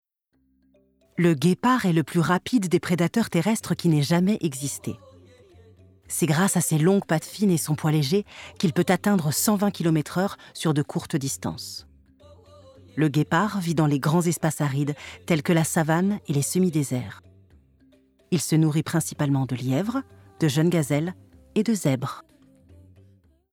Voix off
Documentaire animalier